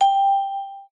soundalert.mp3